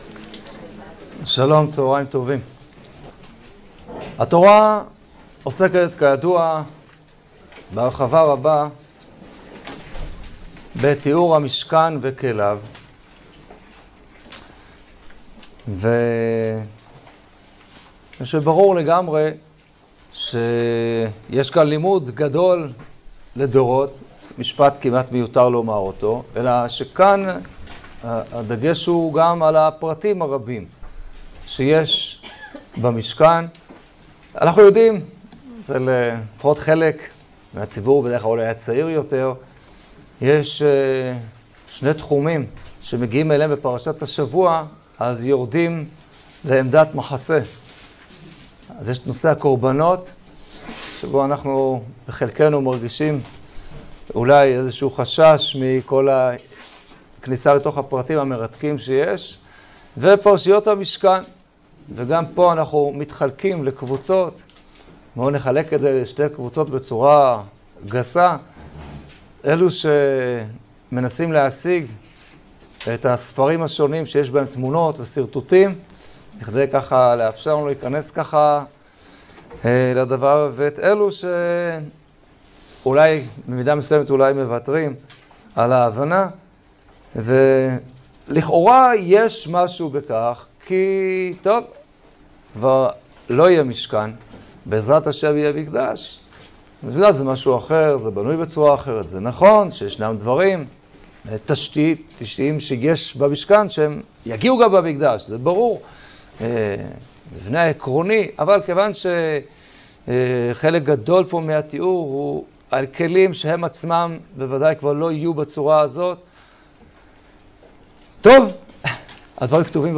השיעור באדיבות אתר התנ"ך וניתן במסגרת ימי העיון בתנ"ך של המכללה האקדמית הרצוג תשע"ח